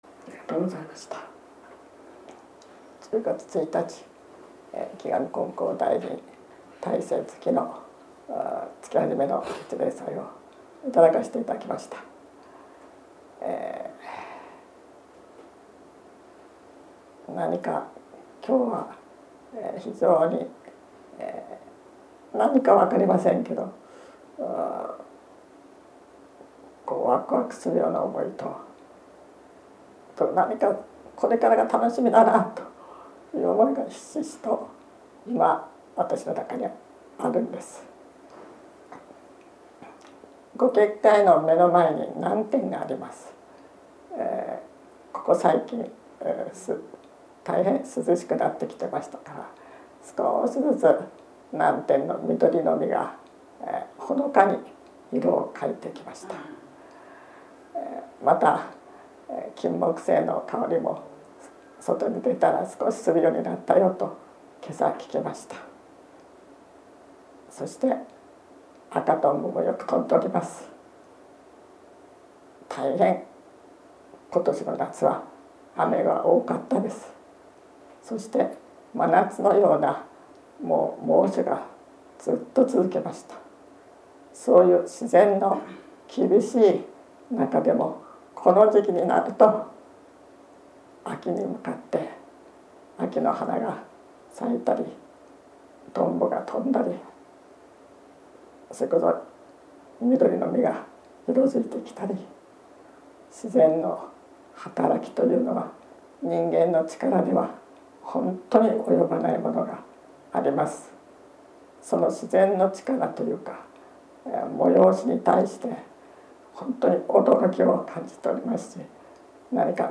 月例祭教話